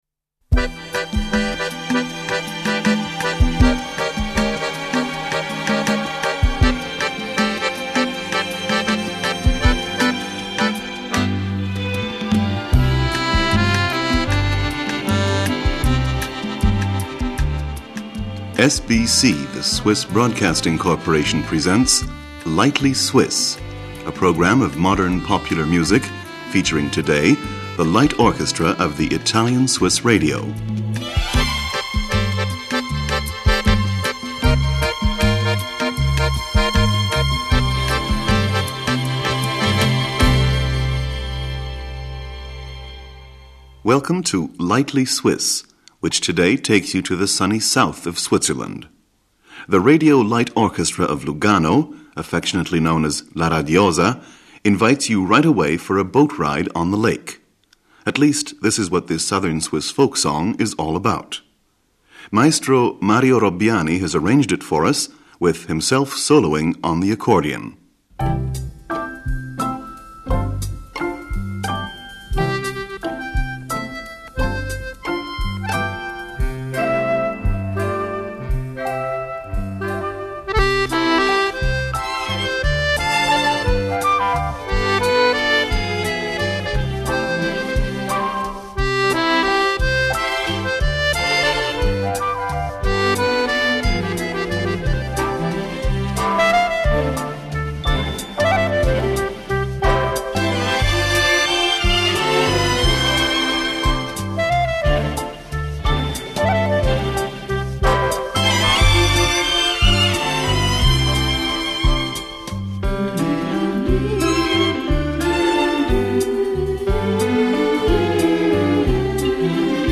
accordion and conductor.
vocal group.
flugelhorn.